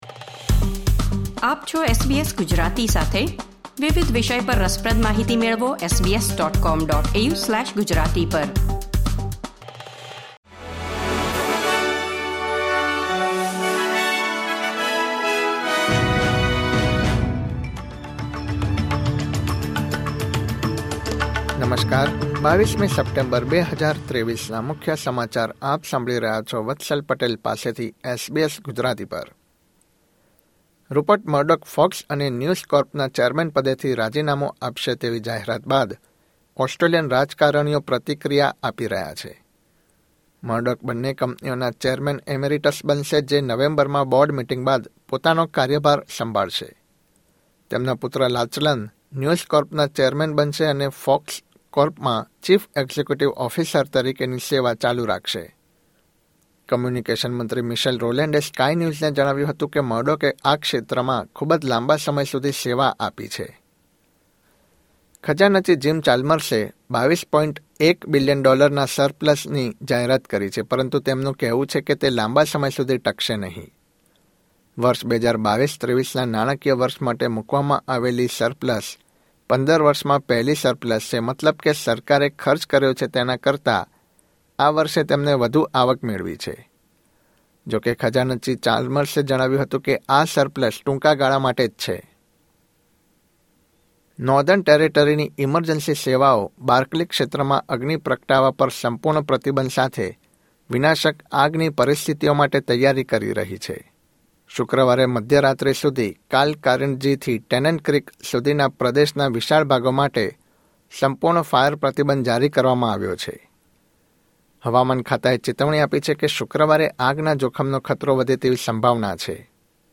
SBS Gujarati News Bulletin 22 September 2023